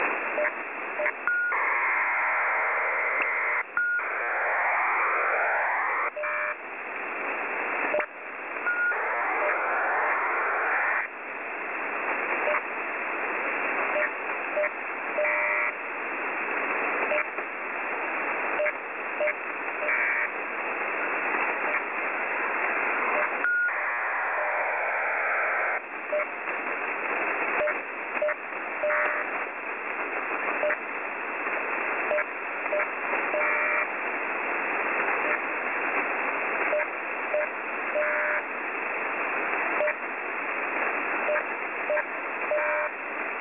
Unknown data signal
Recieved 6712khz